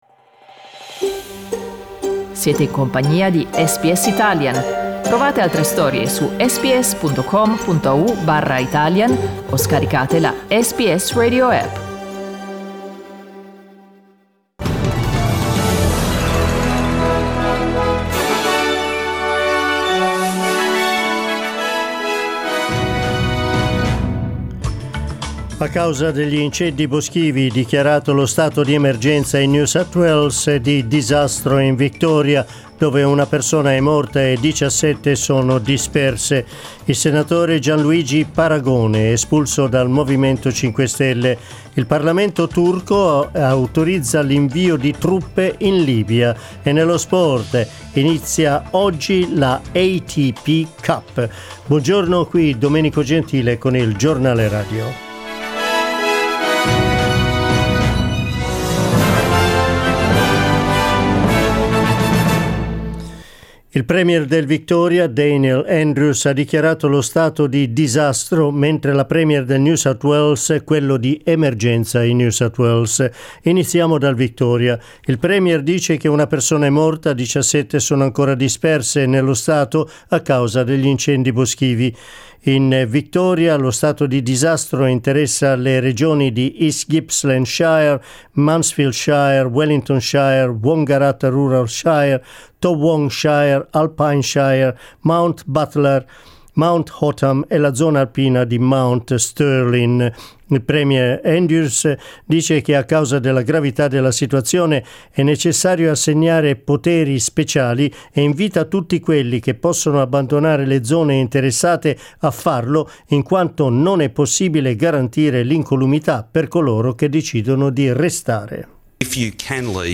Giornale radio 3 gennaio 2020